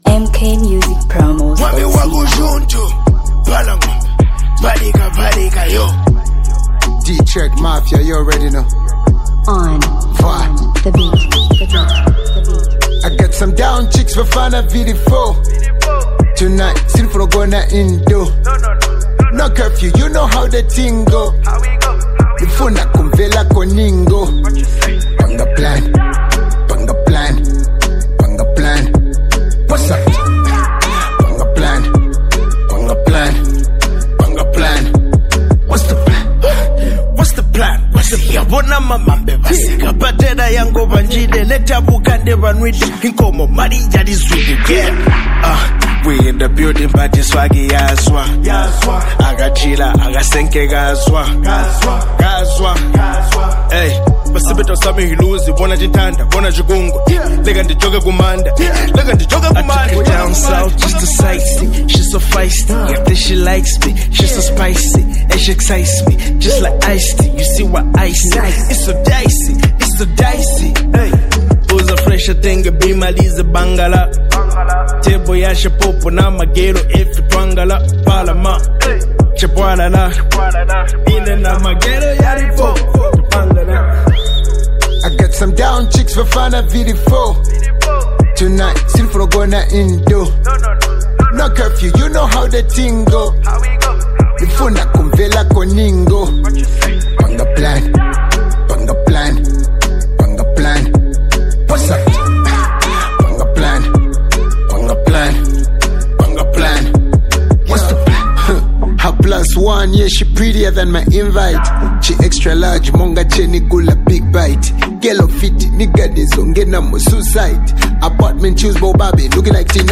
Zambian Hip-Hop Collaboration 2026